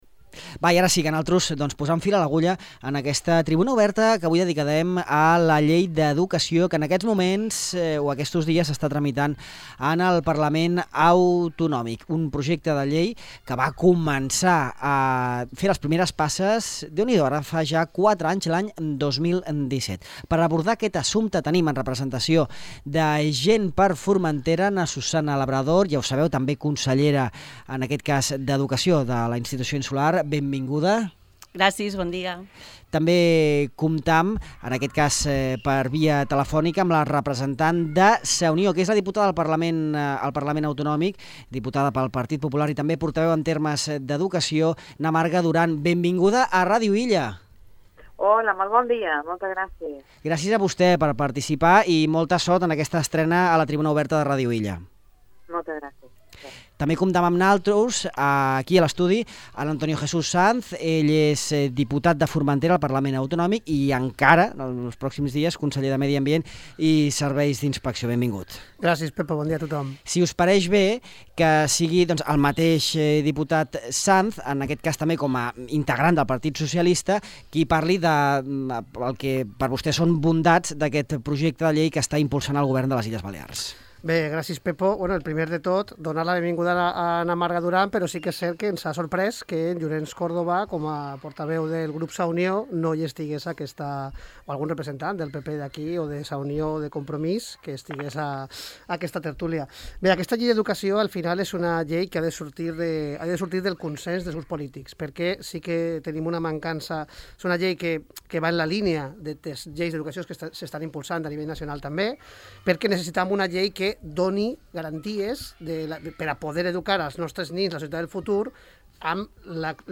La futura Llei d'Educació a les Balears, a la tertúlia política